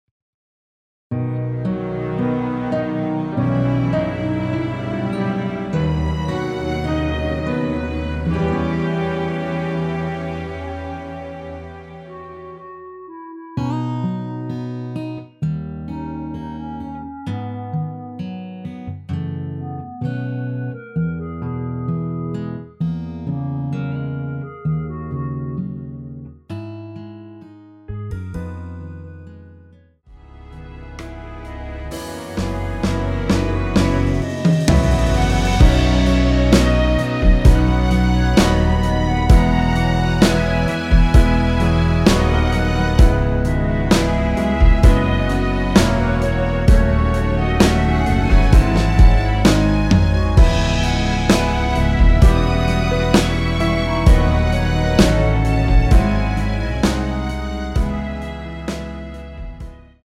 원키에서(-3)내린 멜로디 포함된 MR입니다.(미리듣기 확인)
앞부분30초, 뒷부분30초씩 편집해서 올려 드리고 있습니다.
중간에 음이 끈어지고 다시 나오는 이유는